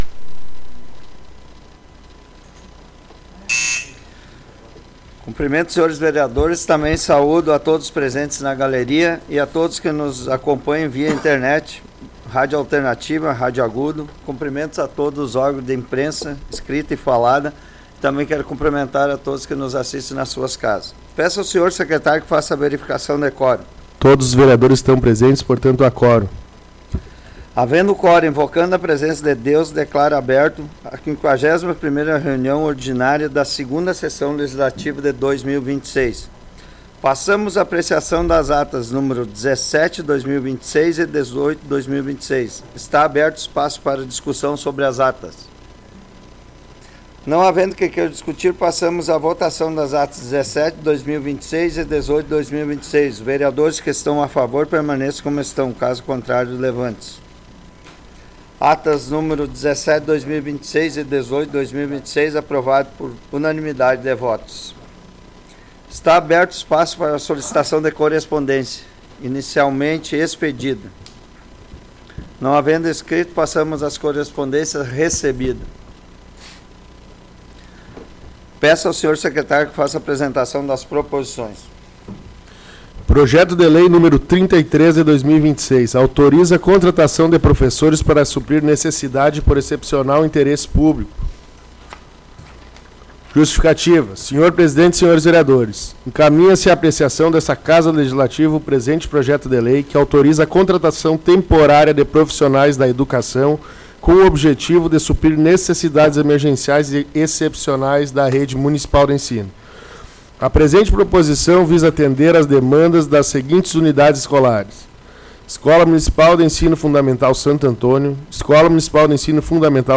Áudio da 51ª Sessão Plenária Ordinária da 17ª Legislatura, de 13 de abril de 2026